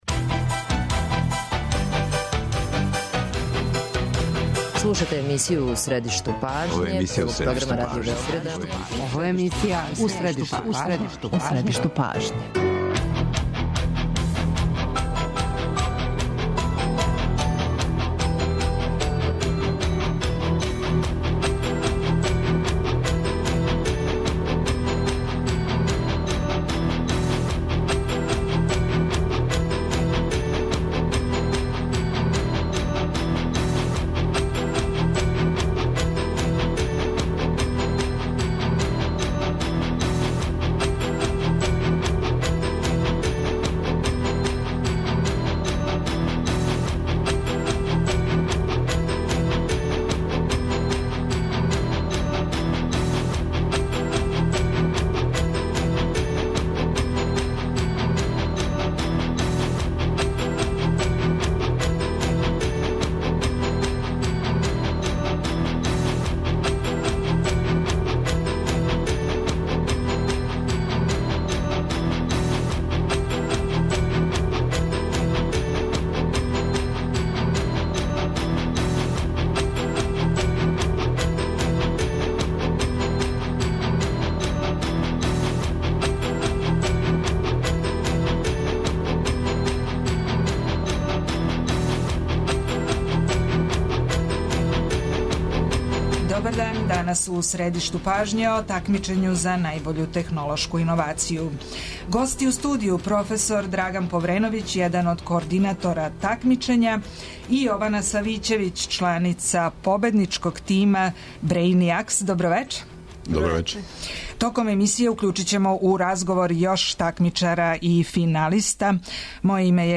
Саговорници емисије су победници, организатори и чланови жирија Такмичења за најбољу технолошку иновацију. Питаћемо их – како се праве и пласирају иновације и да ли су добра идеја и иновација сигуран пут ка успеху на тржишту?